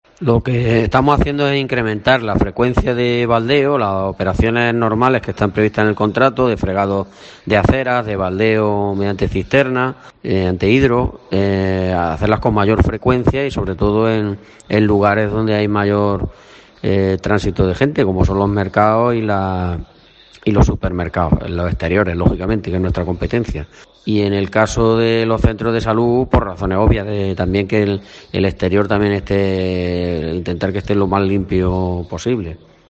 Francisco Lechuga, concejal de Sevicios , nos habla de las medidas extraordinarias de limpieza y desinfección